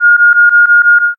suspend-error.wav